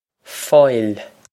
How to Say:
Pronunciation for how to say
Foyle
This is an approximate phonetic pronunciation of the phrase.
This comes straight from our Bitesize Irish online course of Bitesize lessons.